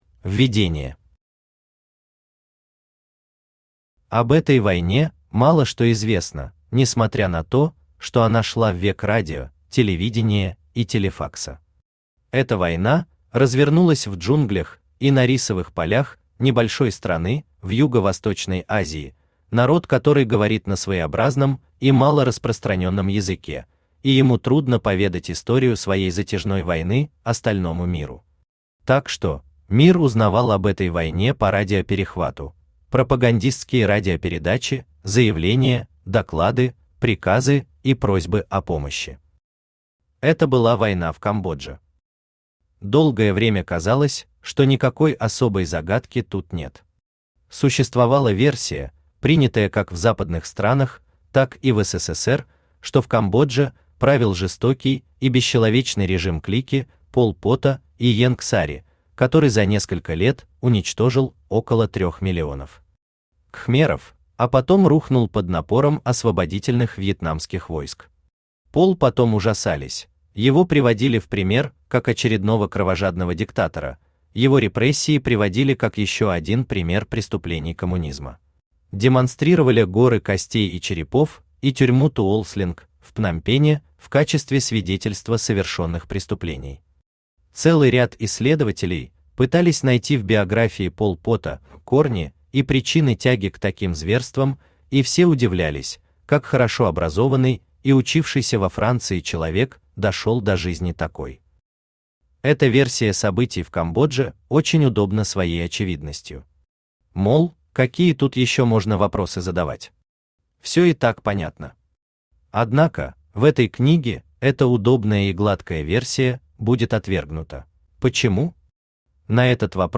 Аудиокнига Война по радиоперехвату | Библиотека аудиокниг
Aудиокнига Война по радиоперехвату Автор Дмитрий Николаевич Верхотуров Читает аудиокнигу Авточтец ЛитРес.